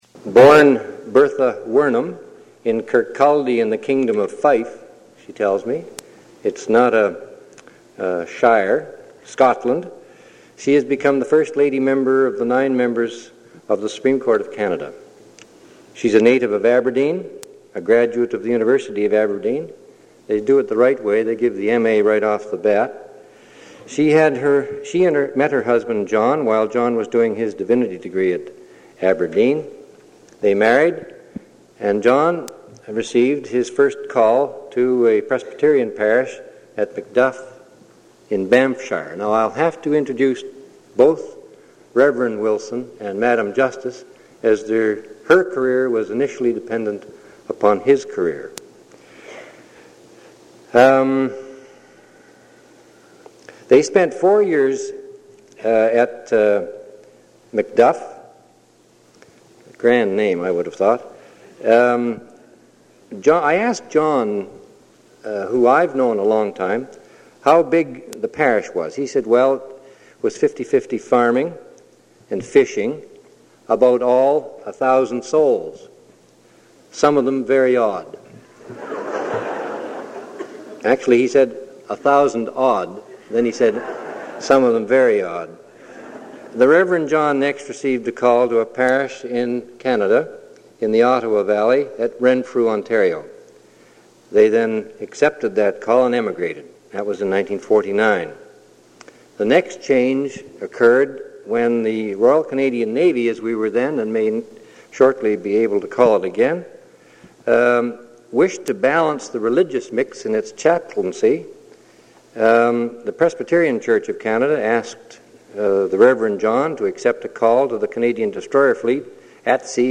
Item consists of a digitized copy of an audio recording of a Vancouver Institute lecture given by Bertha Wilson on September 22, 1984.